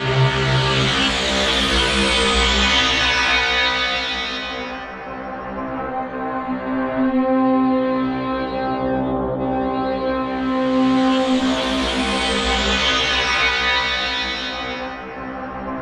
Index of /90_sSampleCDs/Spectrasonic Distorted Reality 2/Partition G/01 DRONES 1